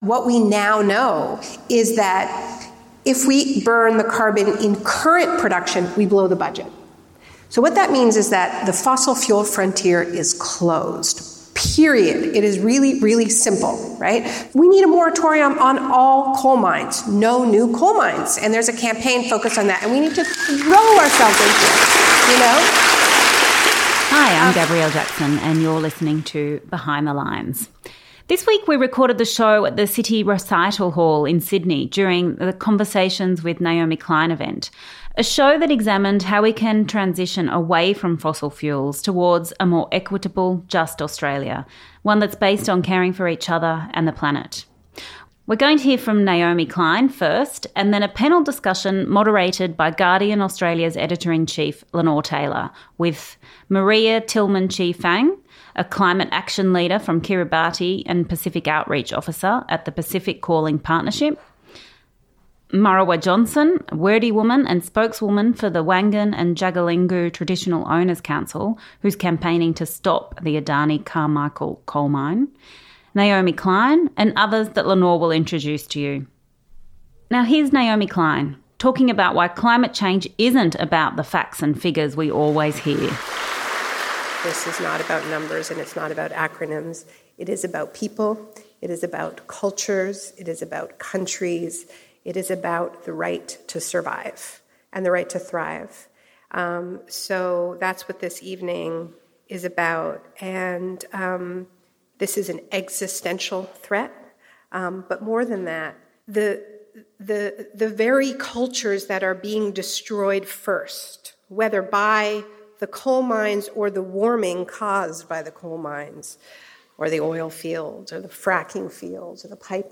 The event highlighted the important work done by women leading their communities in the fight against climate change, and brought together powerful voices from frontline communities fighting for climate justice, the climate science sector, the union movement, the human rights sector and the media.